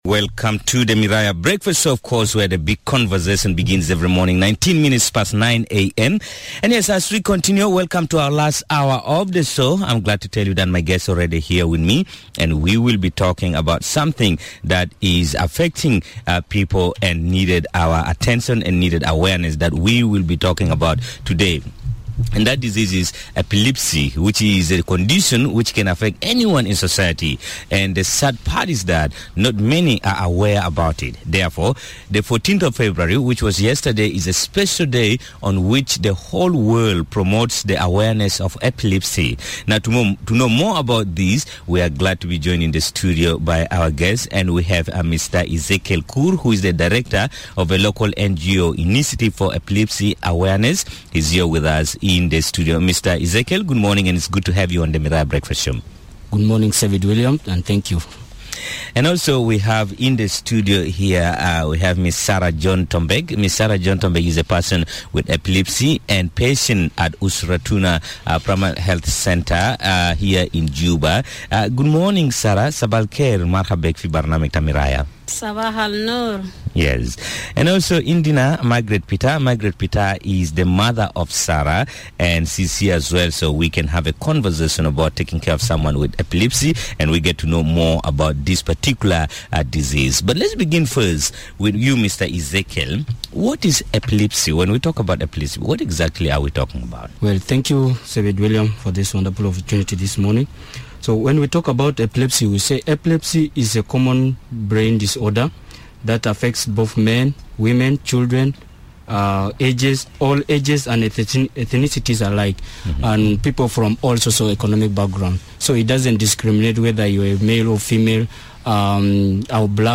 It is for this reason that the 14 of February has been set aside as a special day on which the whole world promotes the awareness of Epilepsy. This morning, Miraya breakfast featured experts live on radio, to explain more about Epilepsy.